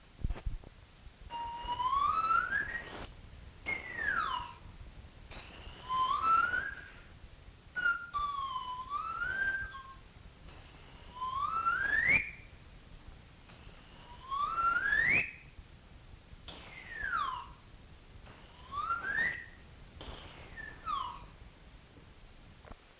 Instrumental. Sifflet végétal
Aire culturelle : Cabardès
Genre : morceau instrumental
Instrument de musique : sifflet végétal